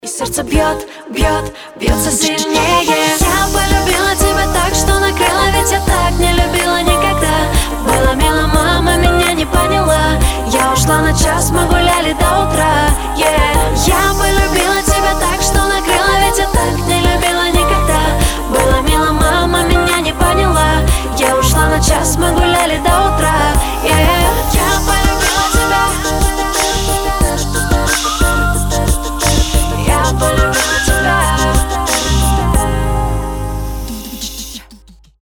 • Качество: 320, Stereo
поп
женский вокал
романтичные